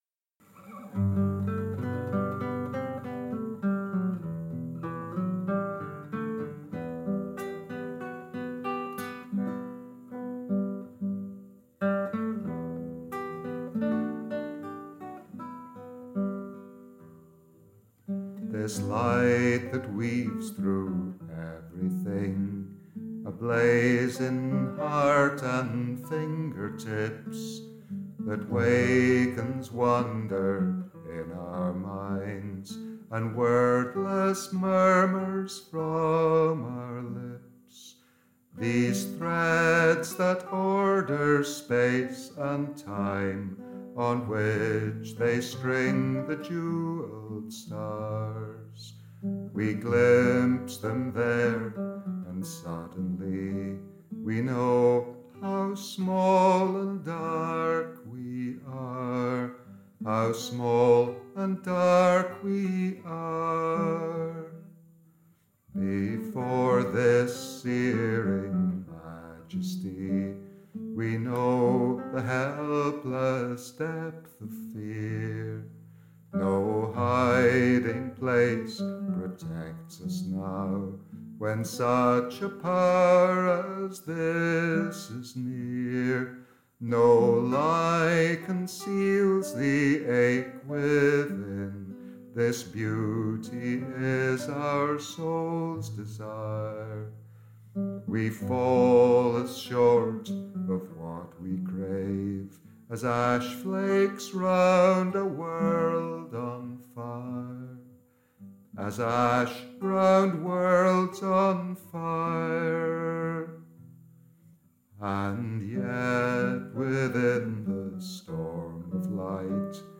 The tune is 'Mary Morison', a traditional tune sung to a Burns song.